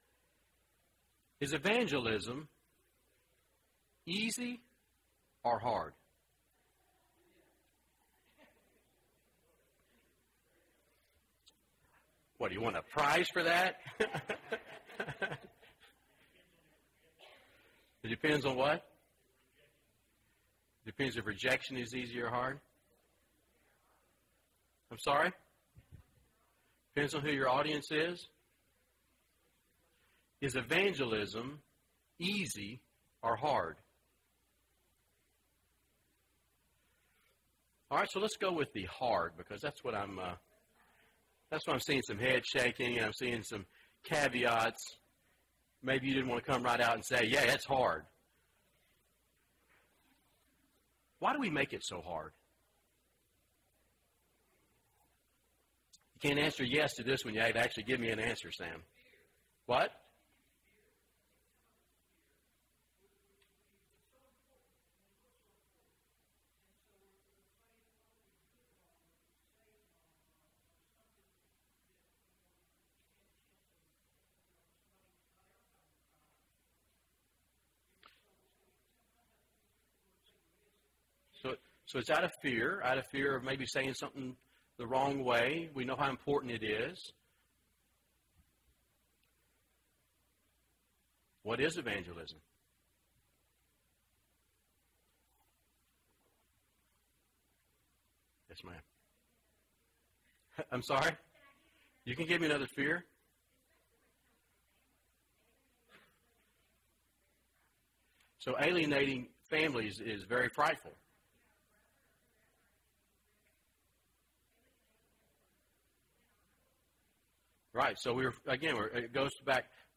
What Can We Learn about Evangelism from a Prophet? (11 of 12) – Bible Lesson Recording